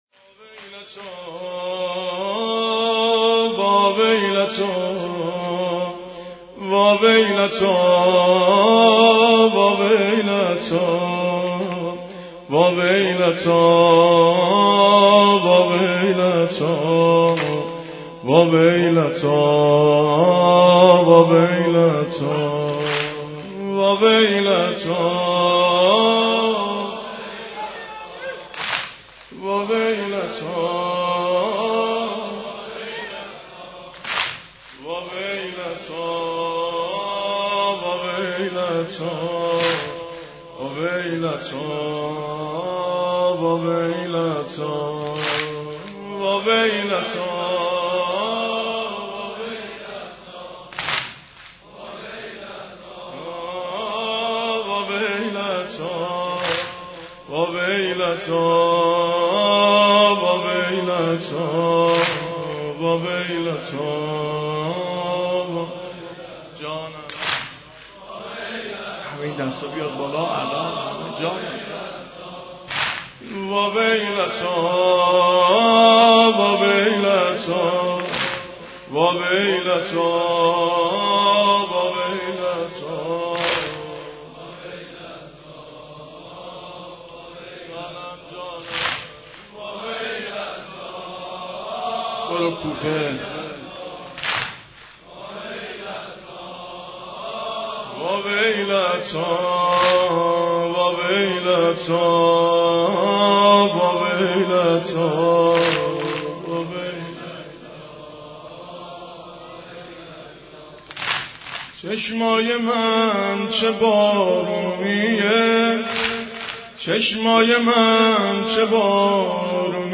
شب اول محرم